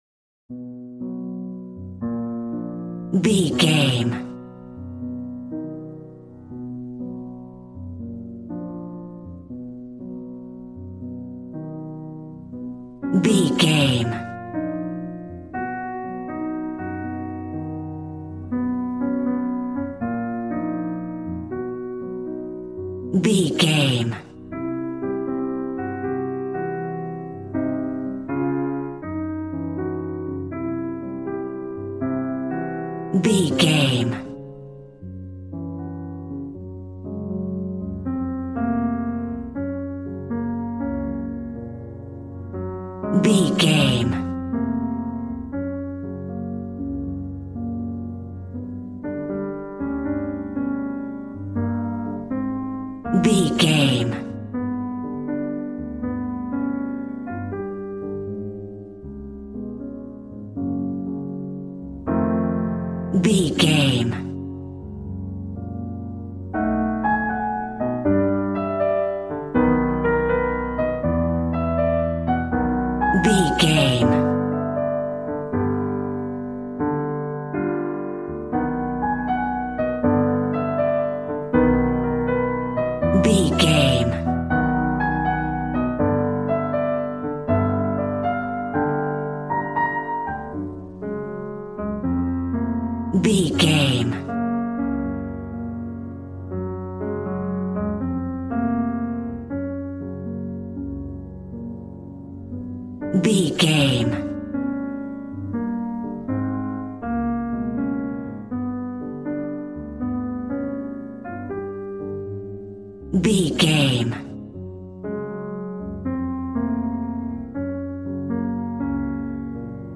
Smooth jazz piano mixed with jazz bass and cool jazz drums.,
Ionian/Major
piano
drums